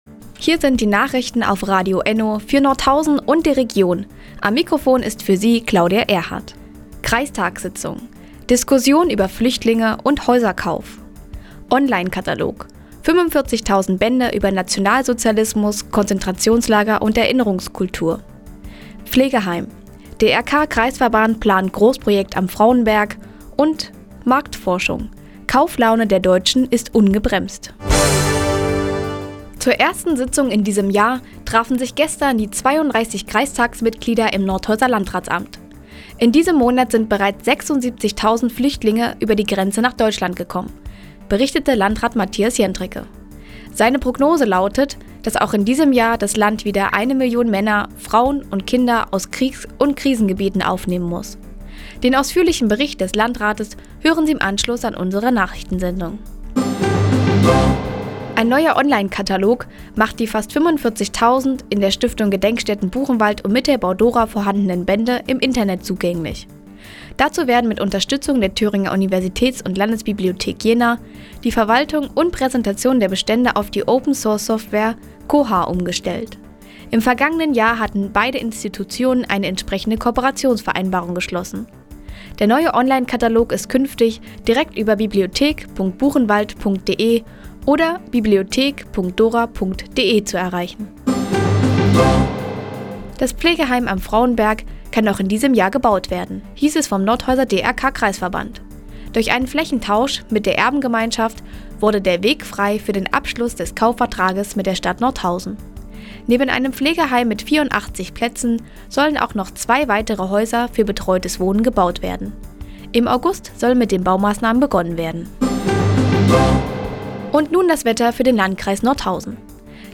Mi, 16:54 Uhr 27.01.2016 Neues von Radio ENNO „Fazit des Tages“ Seit Jahren kooperieren die Nordthüringer Online-Zeitungen und das Nordhäuser Bürgerradio ENNO. Die tägliche Nachrichtensendung ist jetzt hier zu hören.